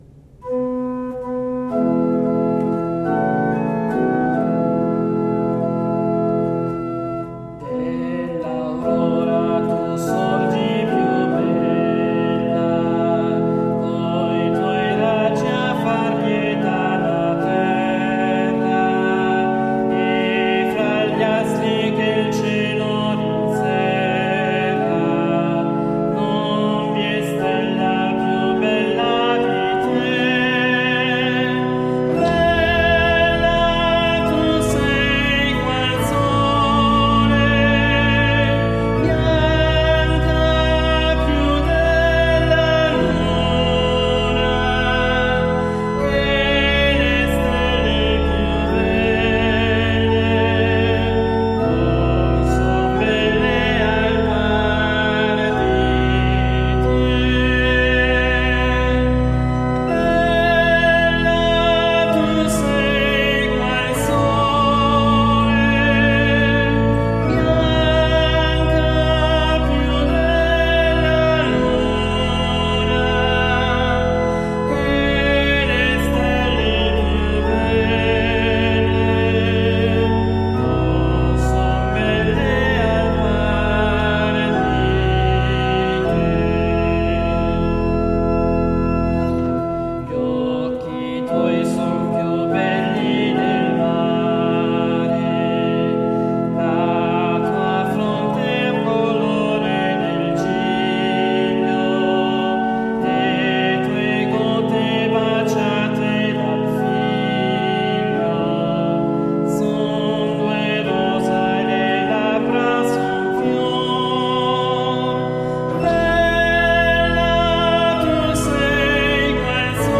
All'organo Agati
organista e solista